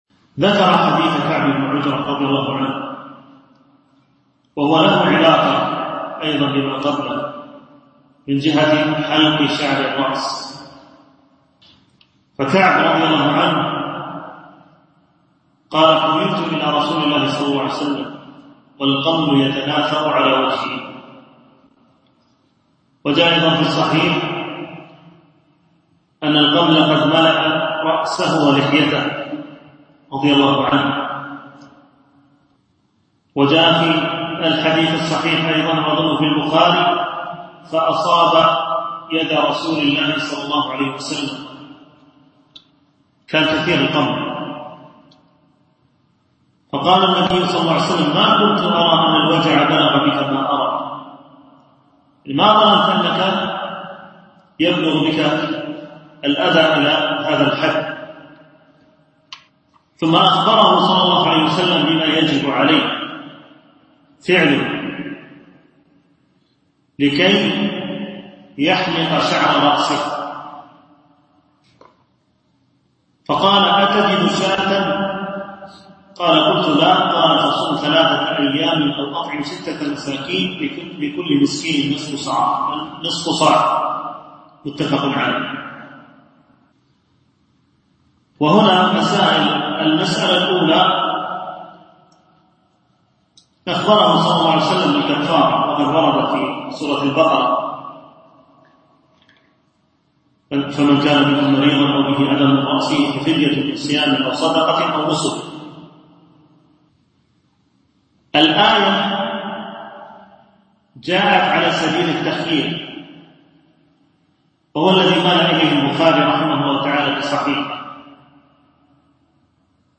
التنسيق: MP3 Mono 22kHz 32Kbps (VBR)